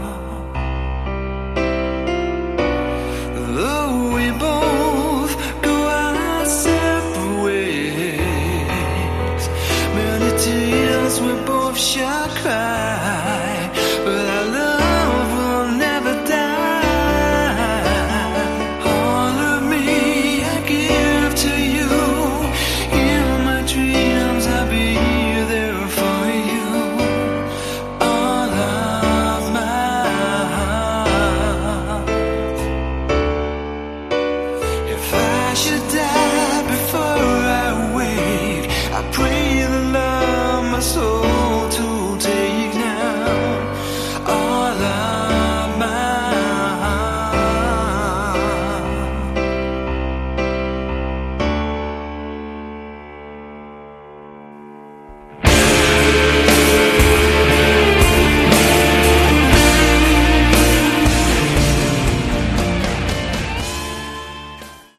Category: Melodic Hard Rock
Vocals
Guitars
Bass
Drums